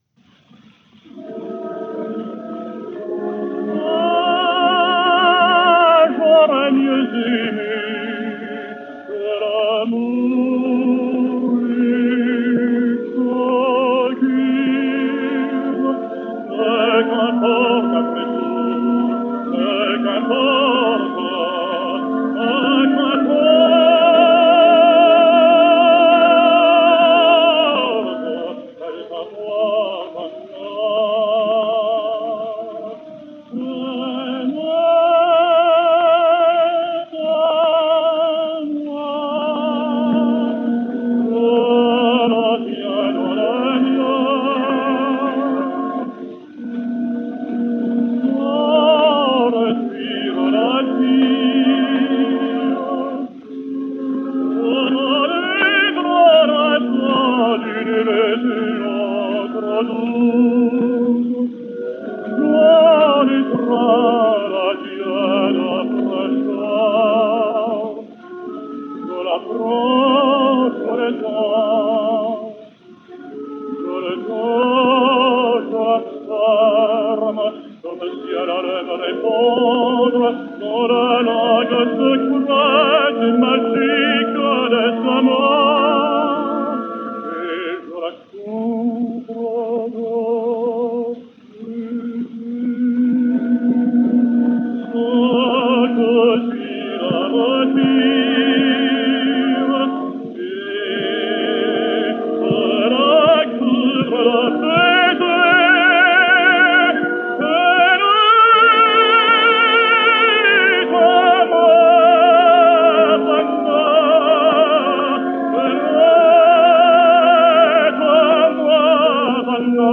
French Tenor.